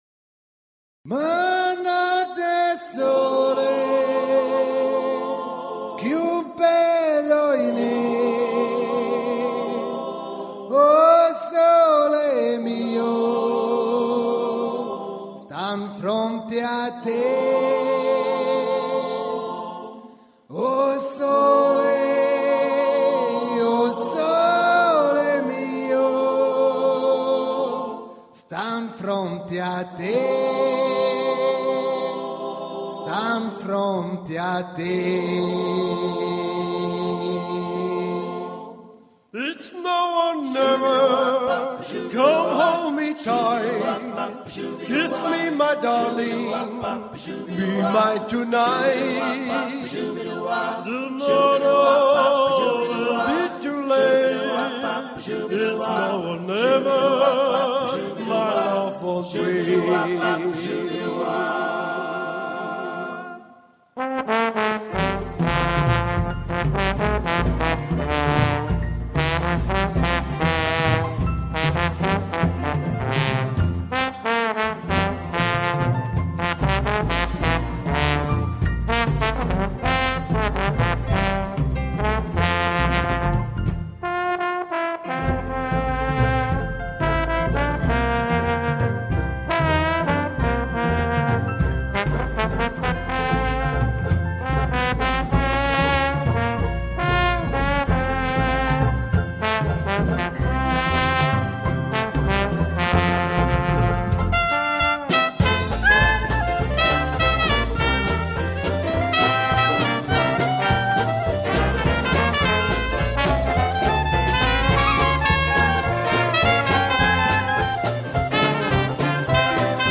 Диксиленд
кларнет
тромбон,рояль
банджо,гитара
ударные. Притом - почти все поют! И не просто поют, а легко и красиво справляются с полифонией.